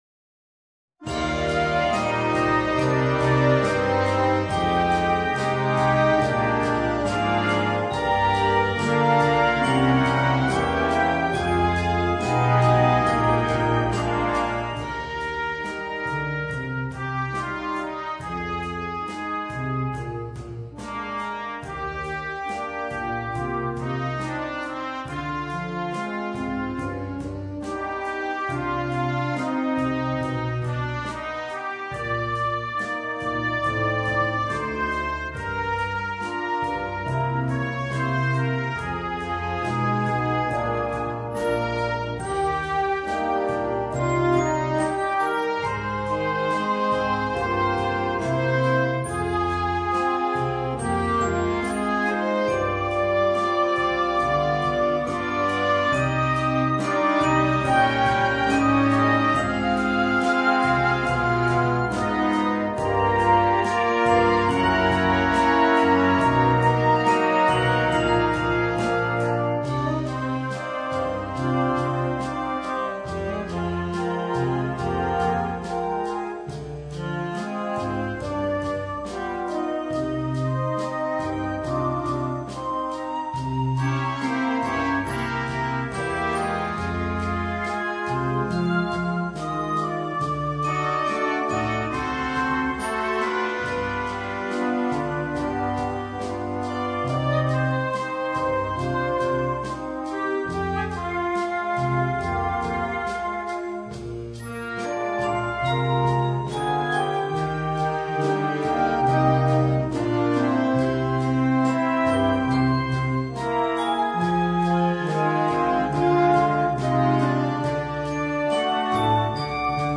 per banda